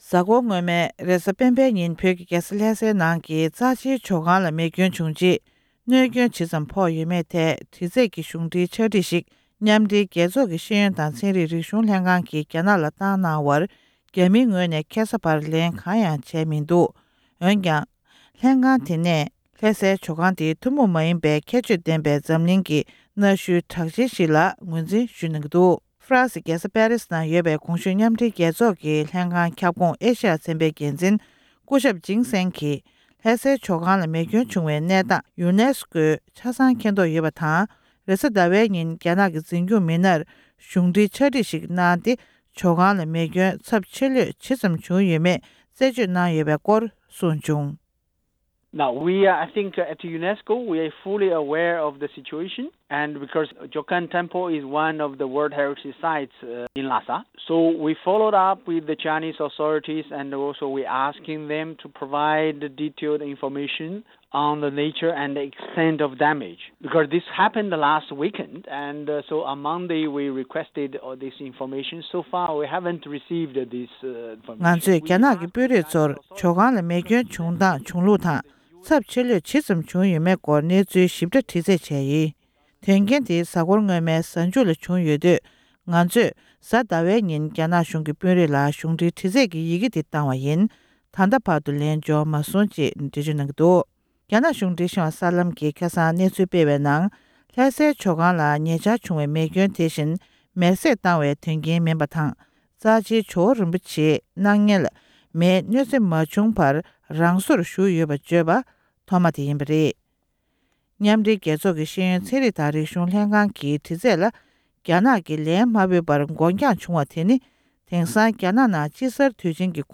འབྲེལ་ཡོད་ལ་བཅར་འདྲི་ཞུས་པའི་གནས་ཚུལ་ལ་གསན་རོགས།